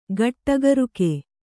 ♪ gaṭṭa garuke